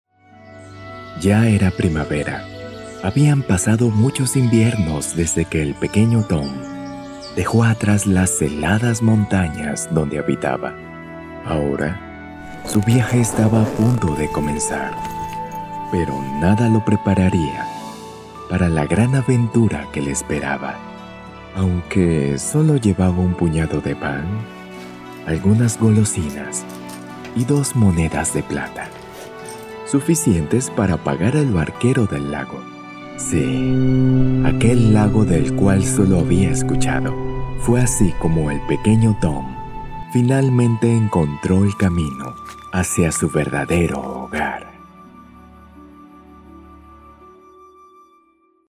Sprechprobe: Sonstiges (Muttersprache):
I will recording spanish male voice over.